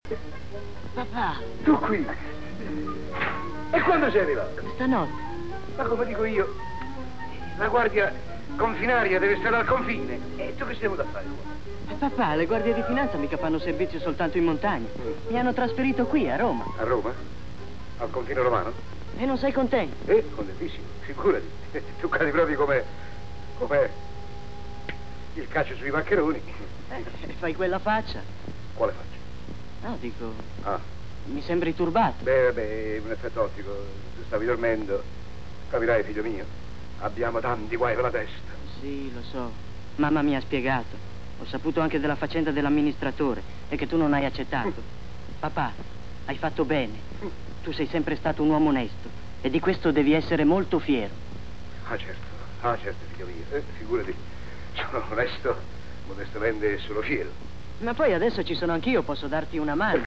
nel film "La banda degli onesti", in cui doppia Gabriele Tinti.